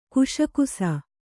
♪ kuśakusa